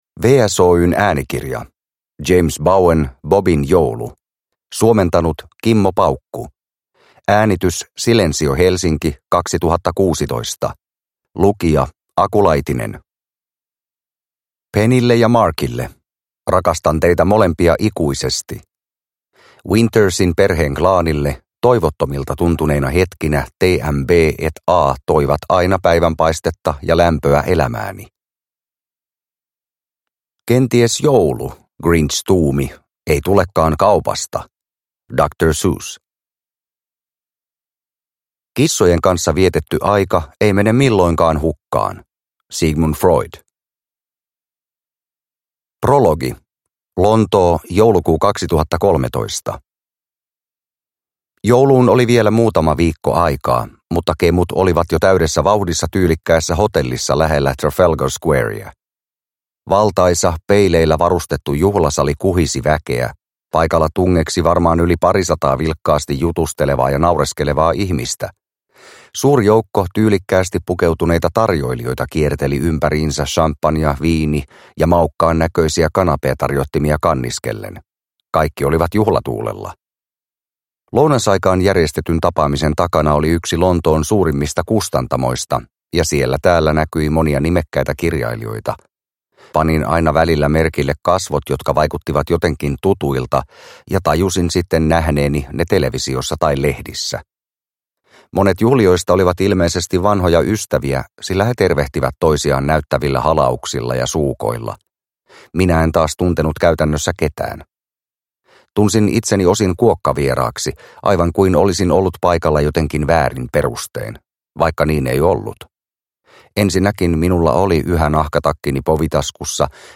Bobin joulu – Ljudbok – Laddas ner